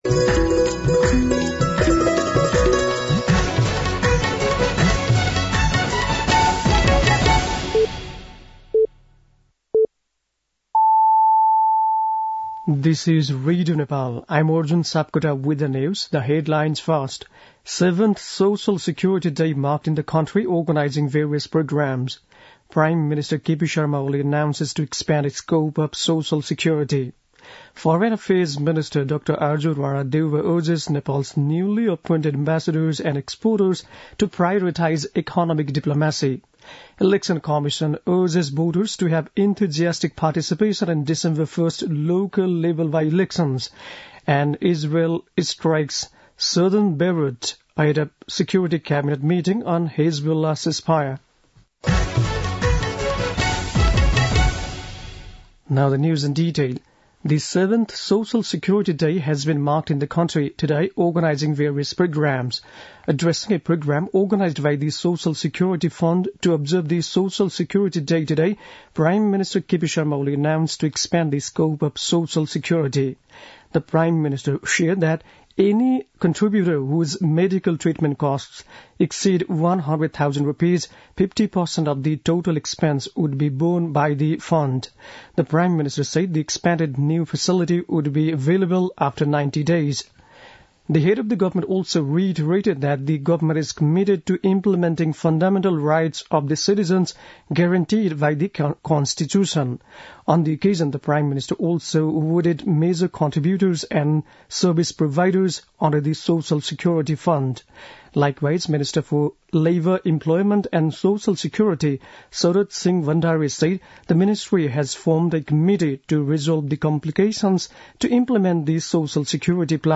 बेलुकी ८ बजेको अङ्ग्रेजी समाचार : १२ मंसिर , २०८१